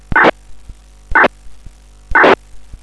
Образец сигнала BIIS (Icom)